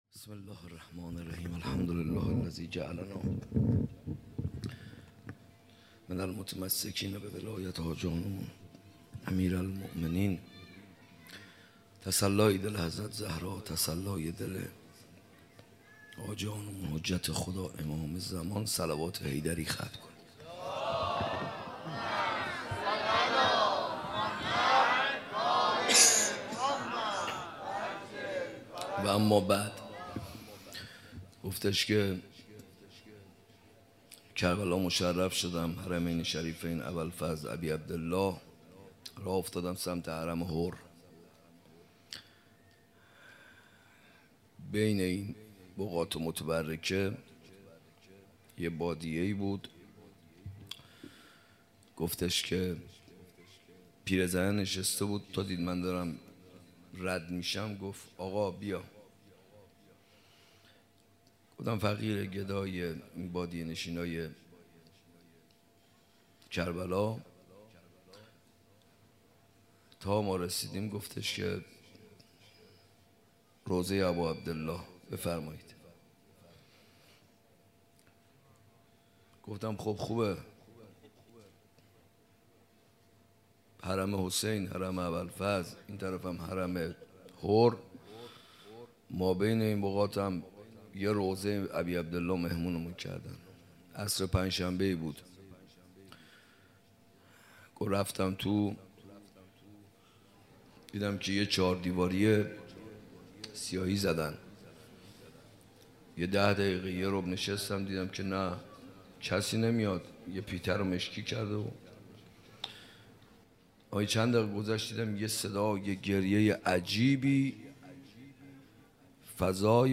حسینیه کربلا
روضه شب عاشورا - شب عاشورا 1399